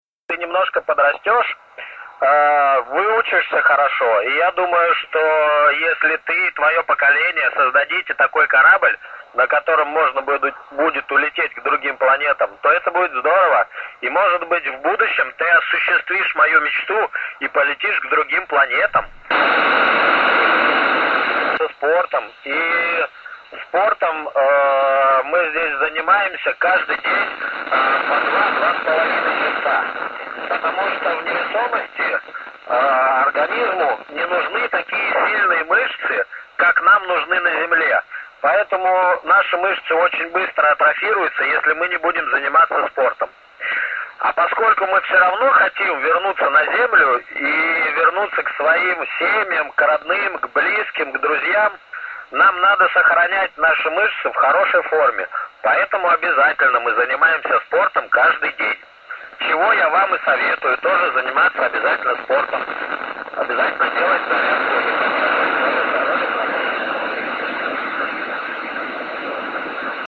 speak russian , signal 9 plus 11:39-1147 UTC ;a short example